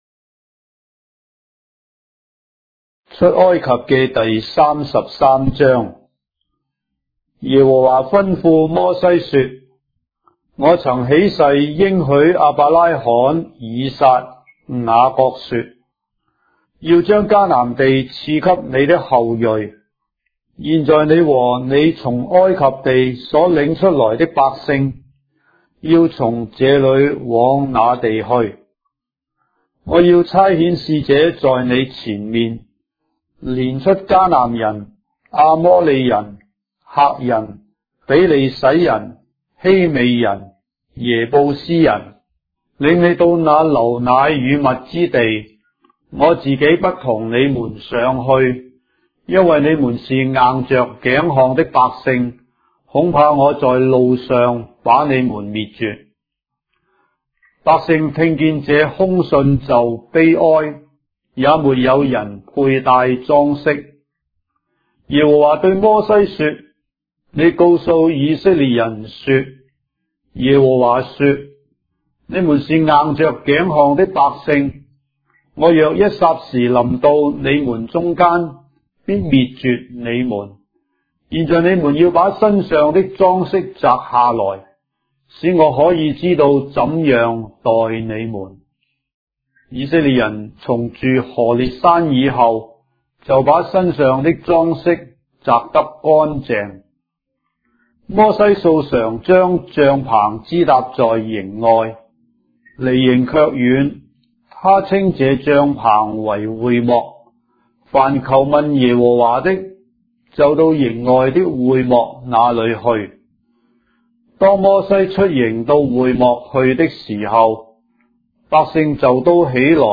章的聖經在中國的語言，音頻旁白- Exodus, chapter 33 of the Holy Bible in Traditional Chinese